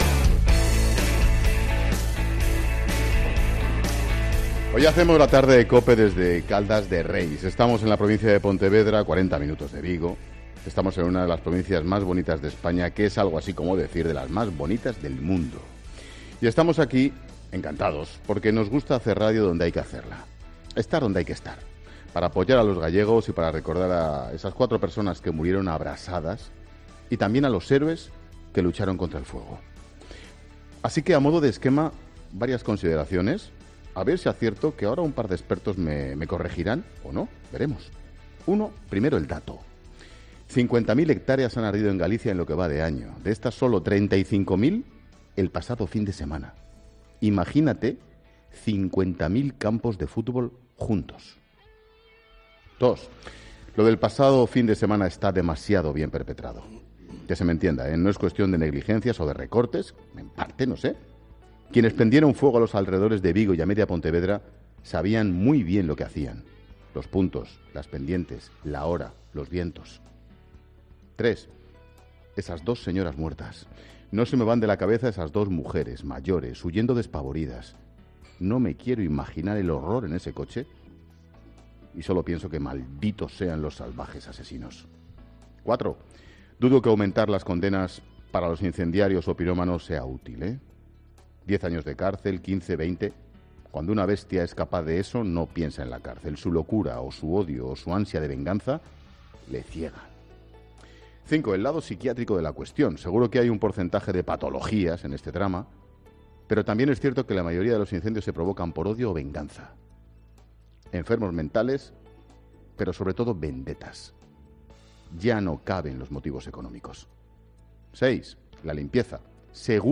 Monólogo de Expósito
El comentario de Ángel Expósito desde Caldas de Reis (Pontevedra).